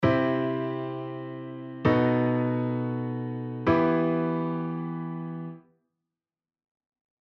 次は、CとEmの間に、キー＝EmのV7であるB7を挟んで、C⇒B7⇒Emの形にしてみます。
B7はキー＝Cにはないコードですが、自然に聞こえますし、「Emが主役になった感」もだいぶ強くなりました。